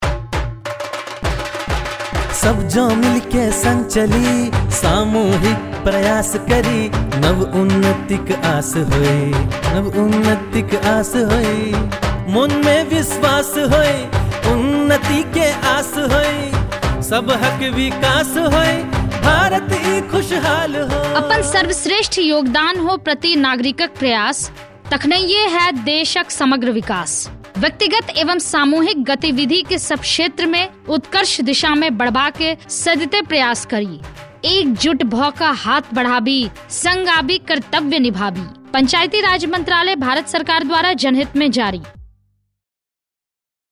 122 Fundamental Duty 10th Fundamental Duty Strive for excellence Radio Jingle Maithili